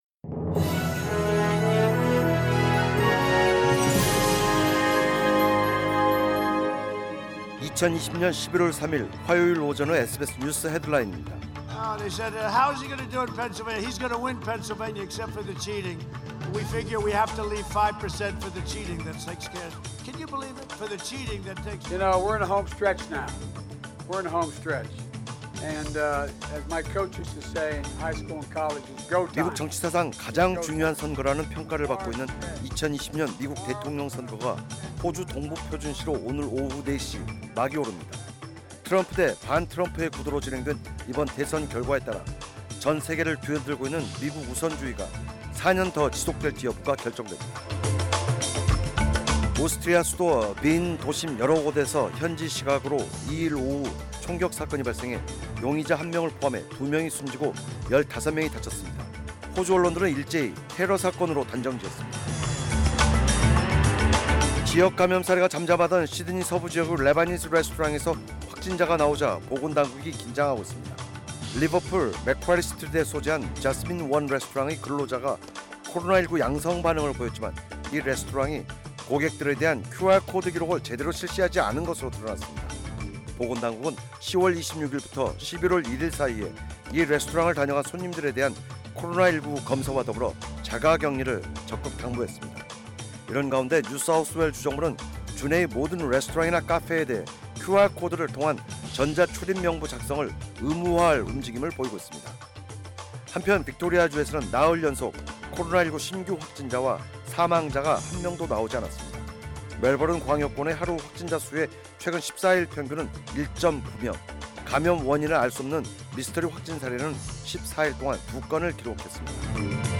2020년11월 3일 화요일 오전의 SBS 뉴스 헤드라인입니다.